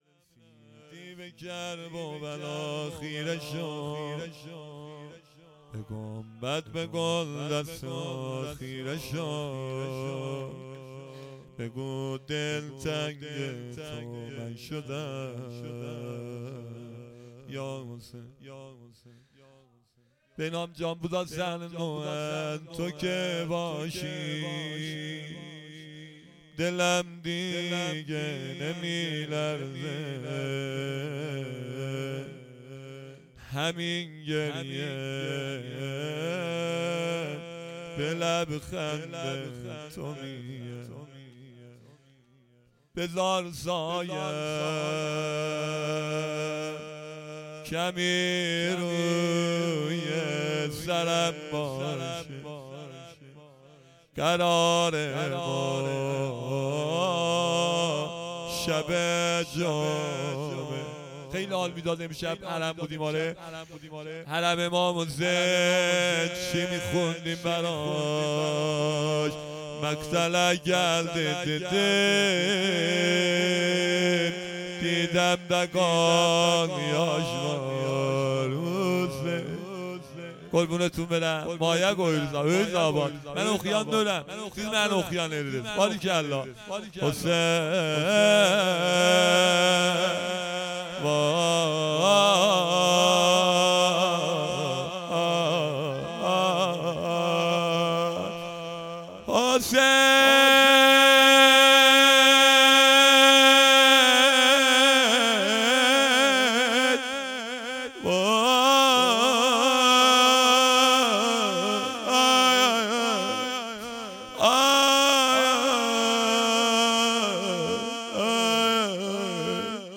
روضه / هیئت انصار الحیدر زنجان